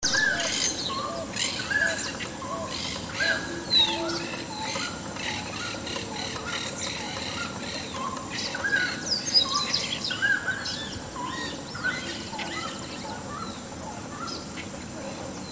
Chiricote (Aramides cajaneus)
Nombre en inglés: Grey-cowled Wood Rail
Localidad o área protegida: Reserva Natural del Pilar
Condición: Silvestre
Certeza: Vocalización Grabada
chiricote.mp3